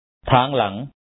tha2aN-la4N behind